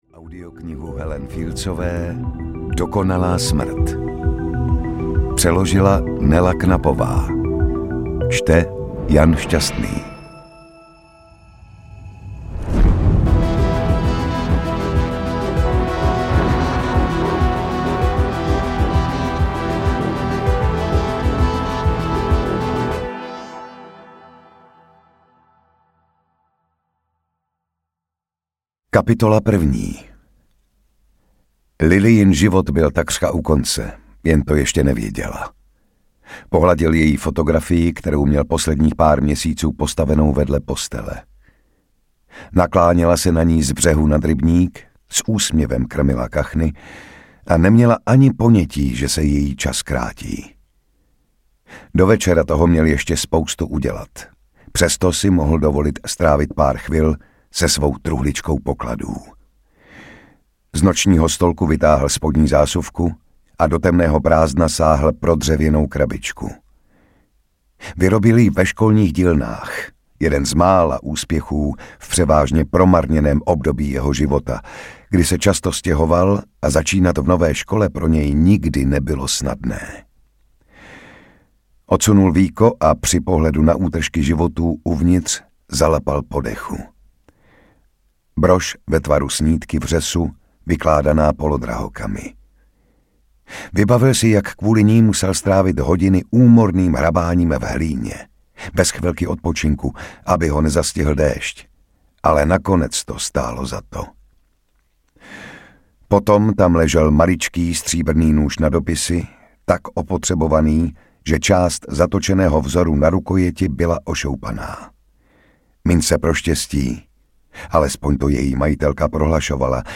Dokonalá smrt audiokniha
Ukázka z knihy
• InterpretJan Šťastný